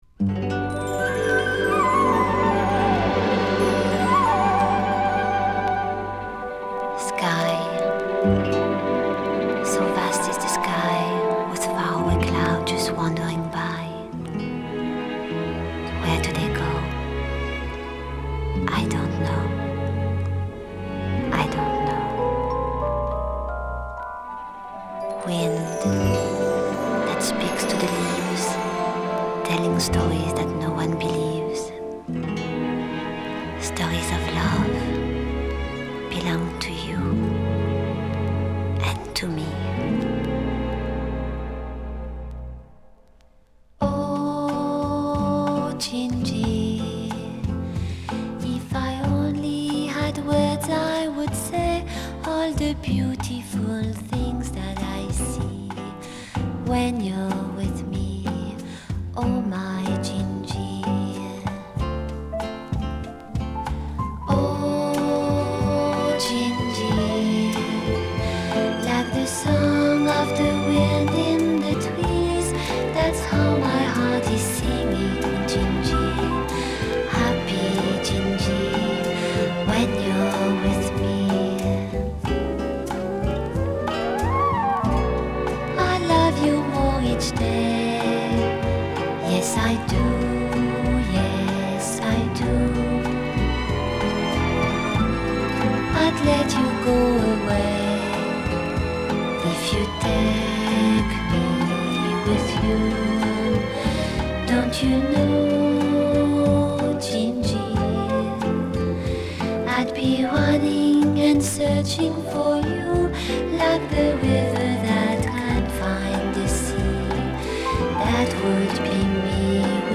Genre: Pop, Vocal, Easy Listening